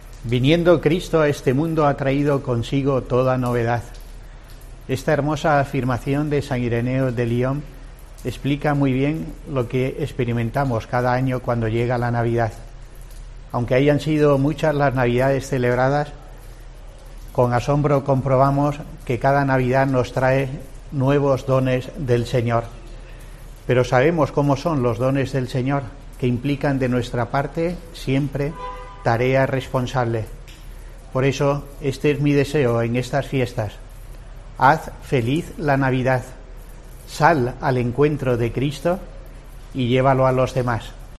Escucha aquí la felicitación navideña del obispo de Asidonia-Jerez que da contenido a su reflexión semanal para la programación religiosa de COPE este viernes